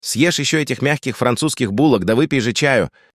man.wav